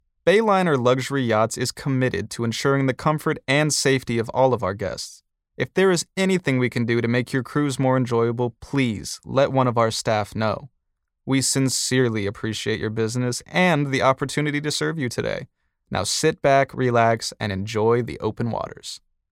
Male
Yng Adult (18-29)
Live Announcer
Words that describe my voice are Versatile, Calm, Natural.